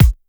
Kick_67.wav